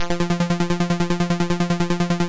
synth7l.wav